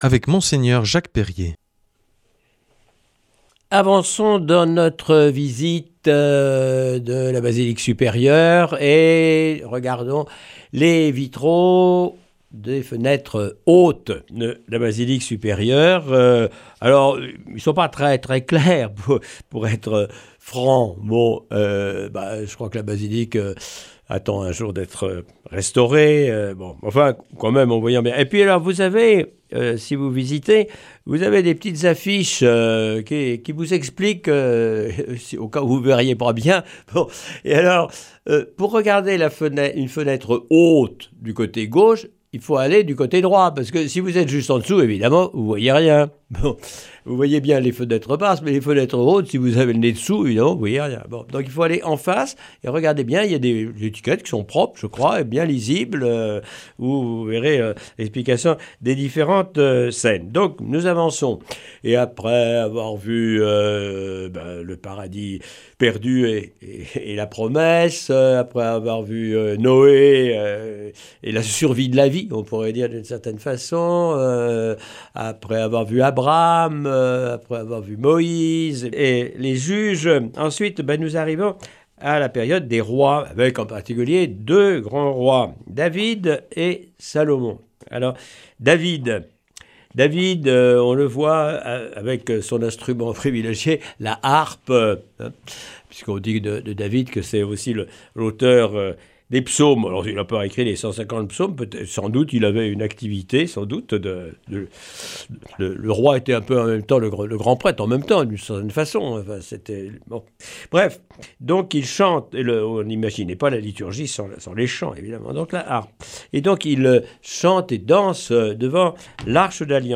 Avec Mgr Jacques Perrier pour guide, nous allons découvrir cette semaine les vitraux ornant la partie haute de la basilique de l’Immaculée Conception au Sanctuaire de Lourdes.